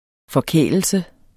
Udtale [ fʌˈkεˀləlsə ]